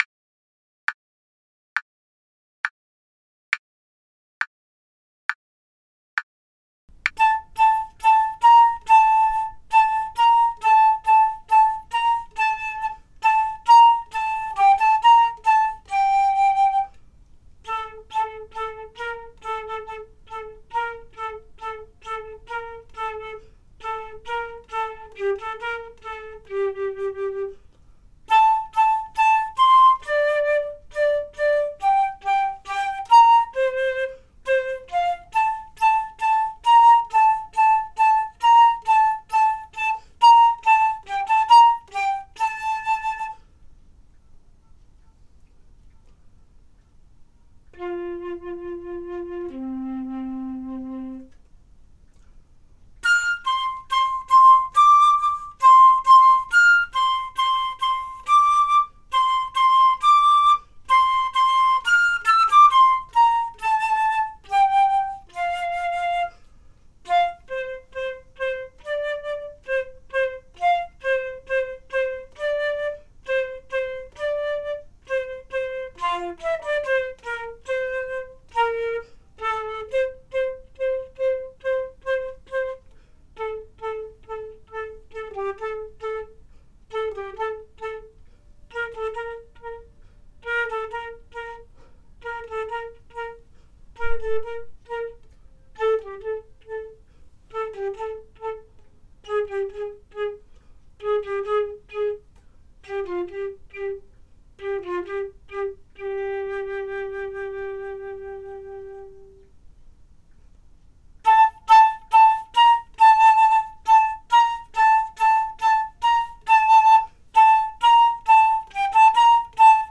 Flute Tunes has a free download of sheet music for a flute trio arrangement of Vivaldi’s Autumn.
Autumn – Part 1 only